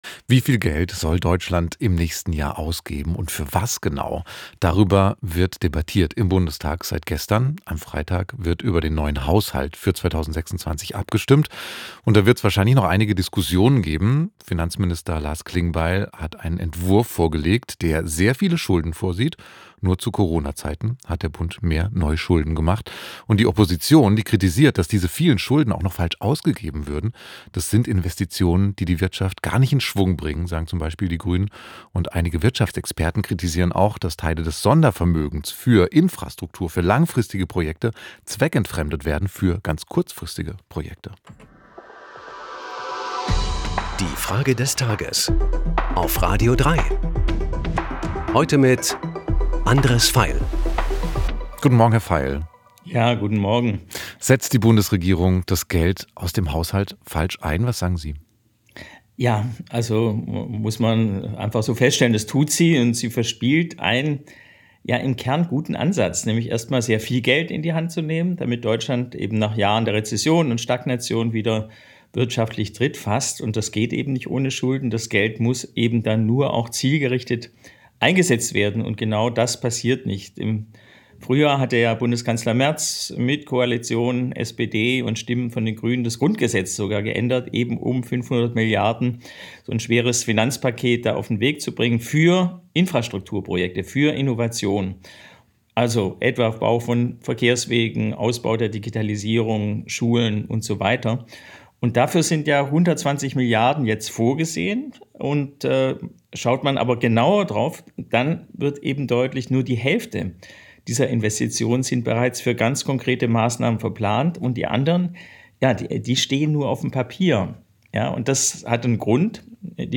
Unsere Frage des Tages an den Filmemacher Andres Veiel